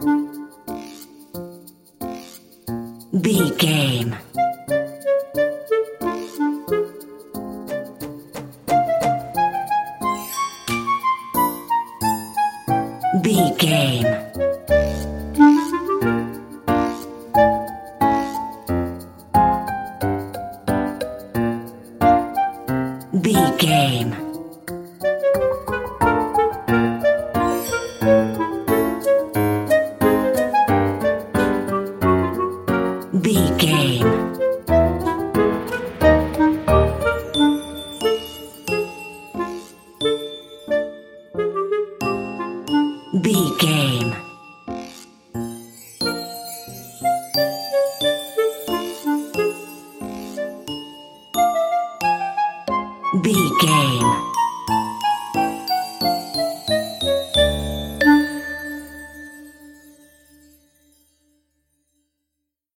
Aeolian/Minor
Slow
flute
oboe
piano
percussion
silly
circus
goofy
comical
cheerful
perky
Light hearted
quirky